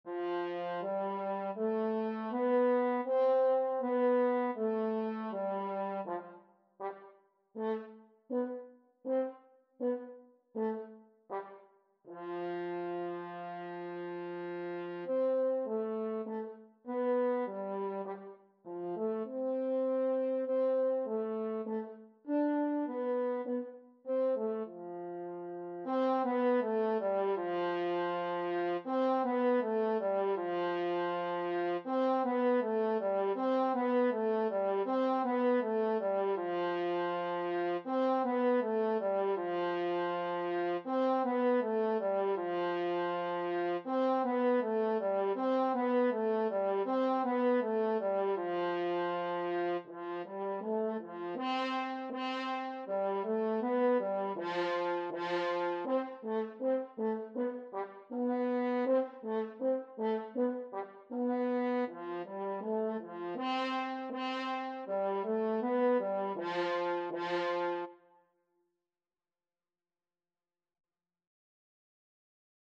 4/4 (View more 4/4 Music)
F4-D5
Beginners Level: Recommended for Beginners
French Horn  (View more Beginners French Horn Music)
Classical (View more Classical French Horn Music)